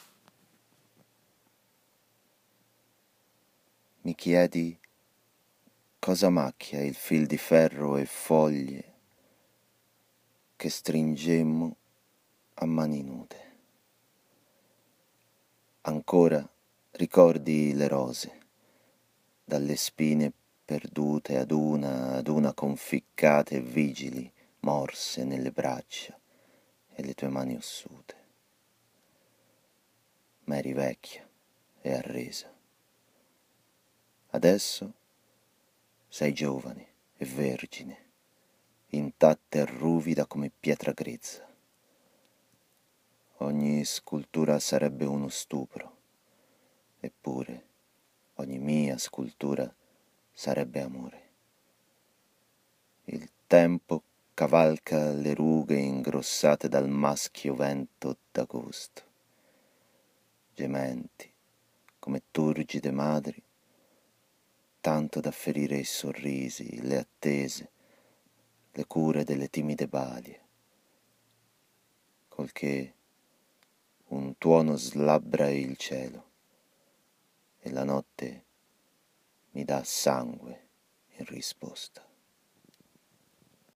Poesia e musica, , , , , , , ,
La canzone è dolce e appassionata, è il giusto ingrediente per addolcire una passione così forte.